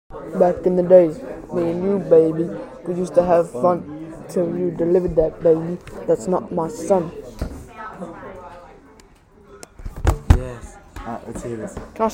Tap Téléchargement d'Effet Sonore
Tap Bouton sonore